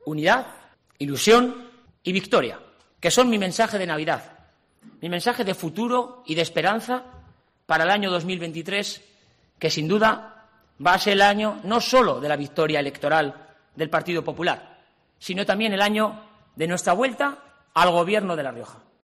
El candidato a la presidencia realizaba estas declaraciones minutos antes de participar en la tradicional cena de Navidad del PP de La Rioja que reunía a más de 500 personas en el restaurante Delicatto de Logroño.